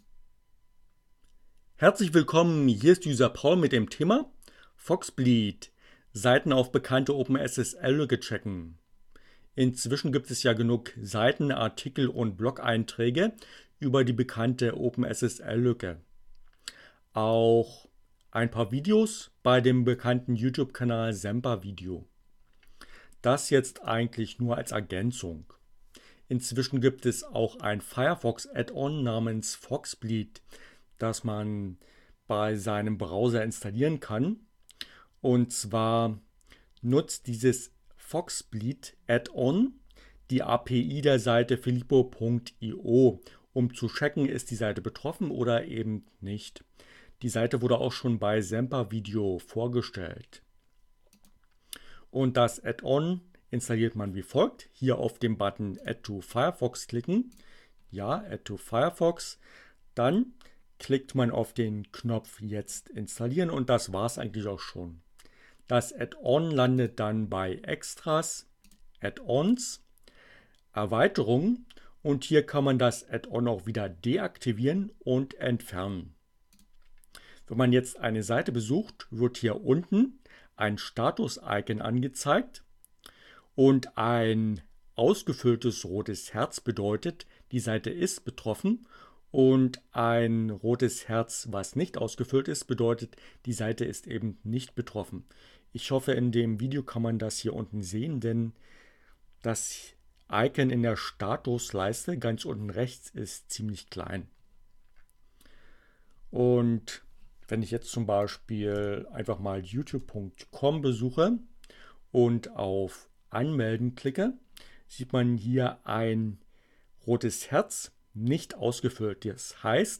Tags: CC by, Linux, Neueinsteiger, ohne Musik, screencast, Heartbleed, FoxBleed, OpenSSL, Web